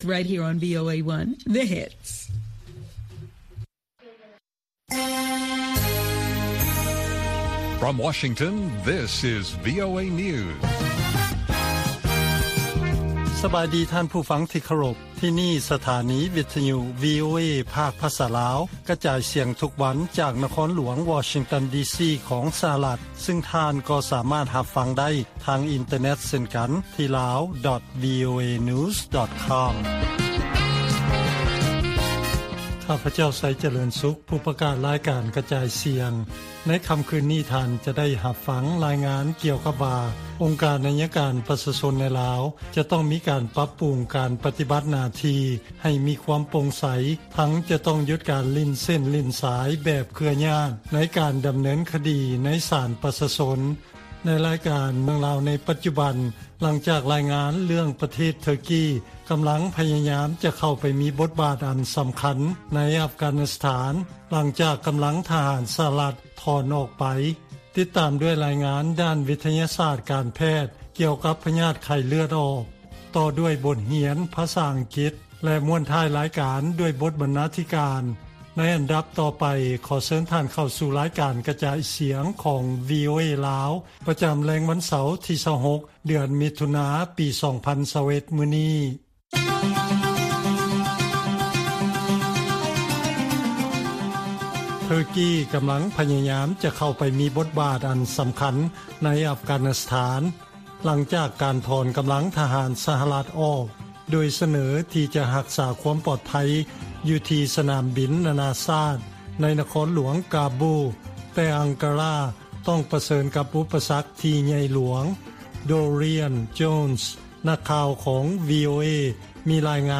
ລາຍການກະຈາຍສຽງຂອງວີໂອເອ ລາວ: ອົງການໄອຍະການປະຊາຊົນໃນລາວ ຈະຕ້ອງມີການປັບປຸງ ການປະຕິບັດໜ້າທີ່ ໃຫ້ມີຄວາມໂປ່ງໃສ